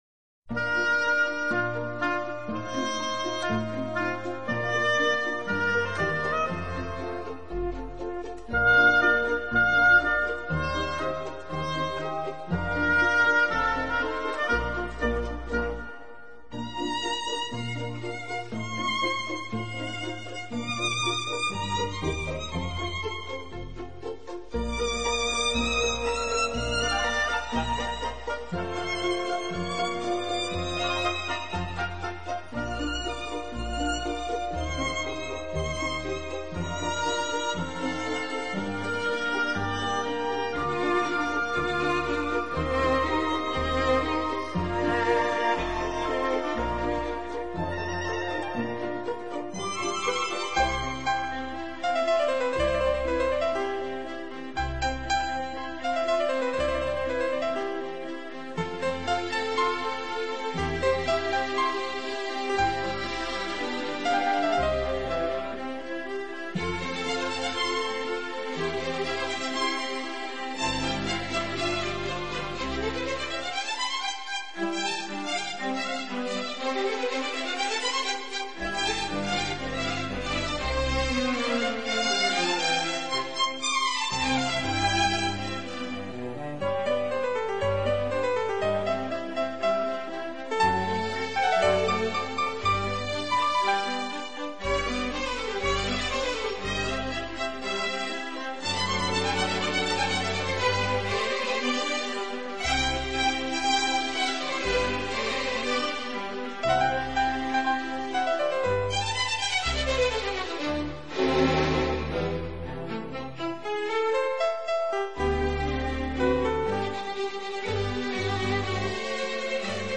音乐类型：跨界融合 Fusion
音乐风格：Neo Classical，室内乐